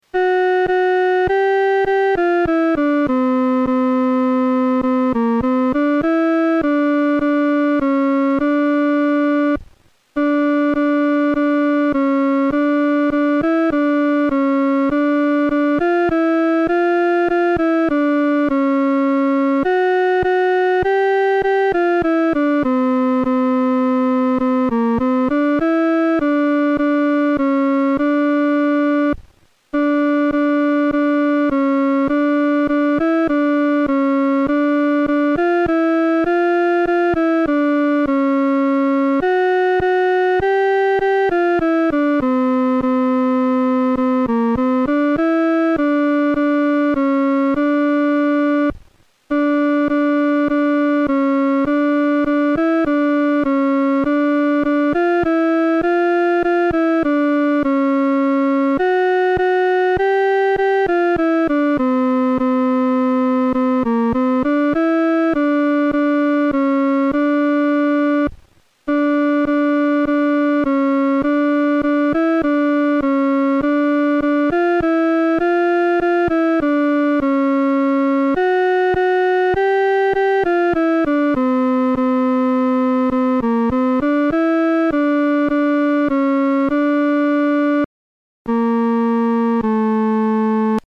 伴奏
女低
这首诗歌宜用不太慢的中速弹唱。